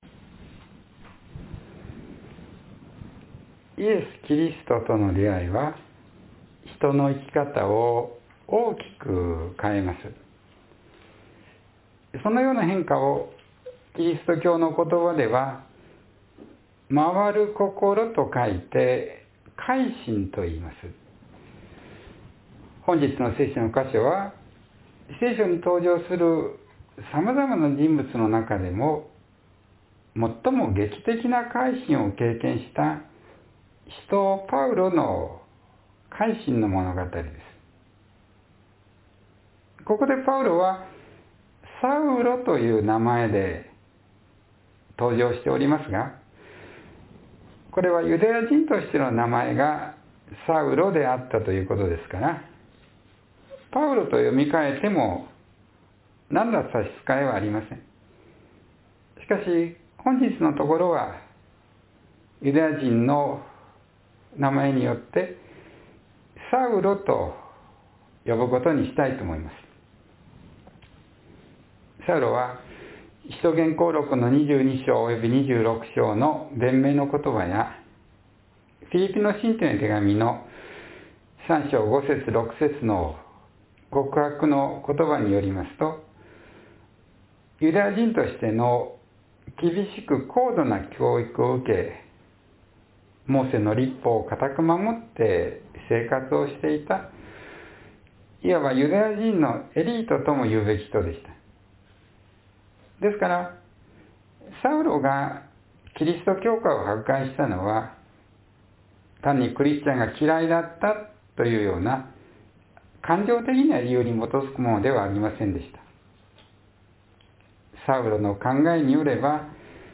（11月6日の説教より）